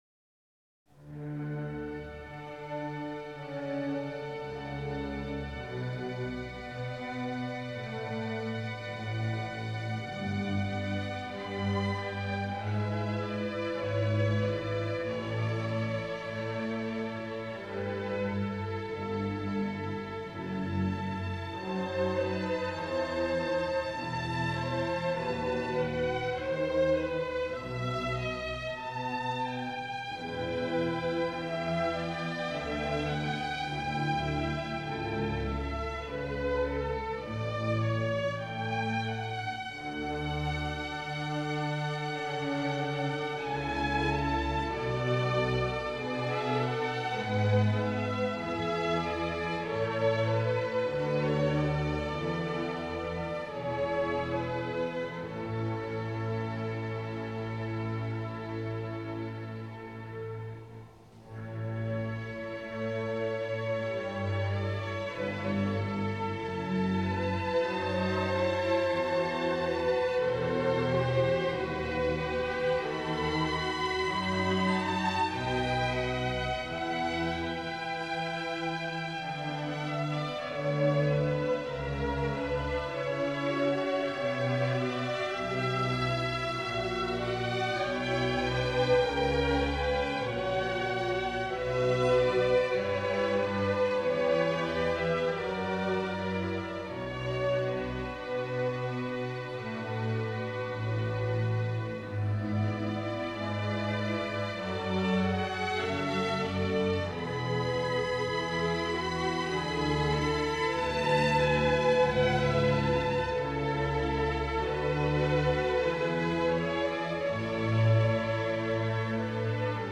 This version is by The Royal Philharmonic Orchestra.